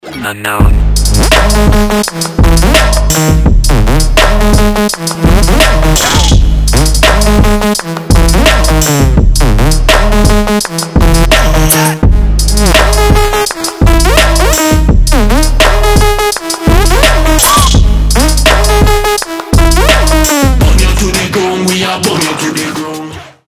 • Качество: 320, Stereo
мужской голос
ритмичные
громкие
dance
EDM
Trap
Bass
бодрые
Классные басы, классный трэпчик